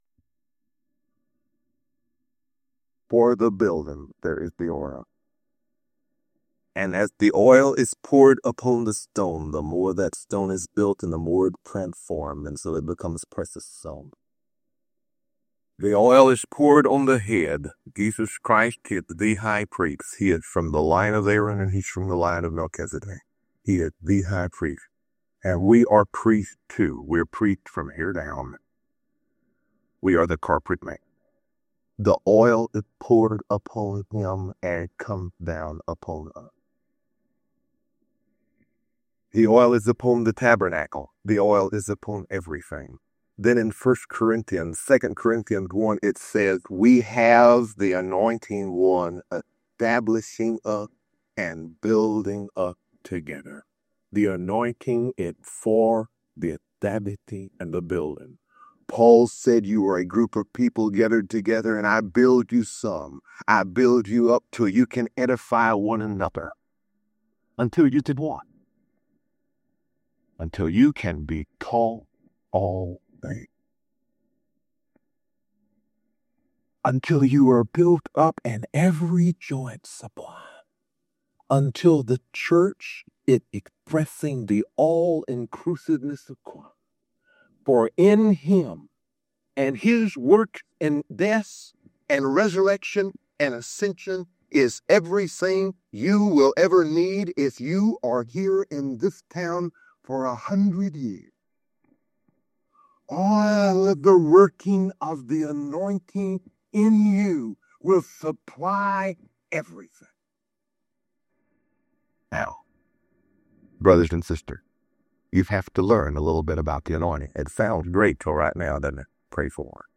This teaching reveals that the anointing is not merely a doctrine, but an active, inward working.